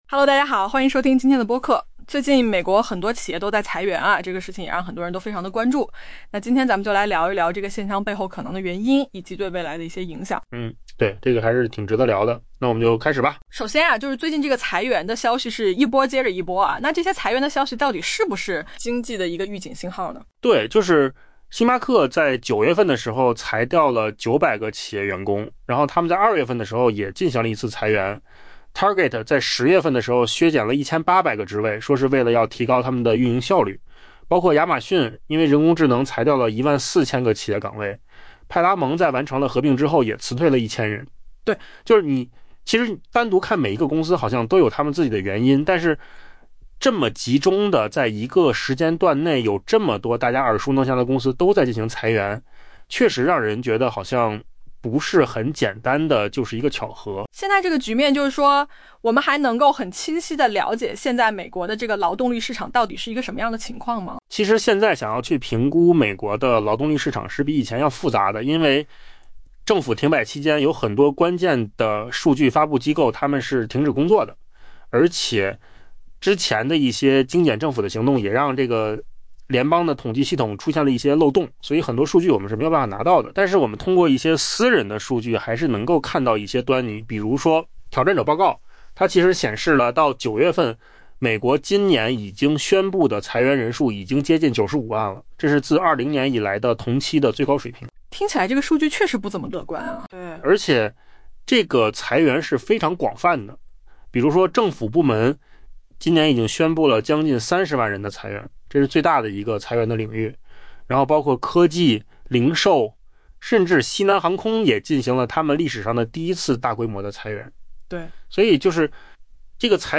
AI 播客：换个方式听新闻 下载 mp3 音频由扣子空间生成 当星巴克在 9 月解雇 900 名企业员工时，经济学家们几乎无动于衷。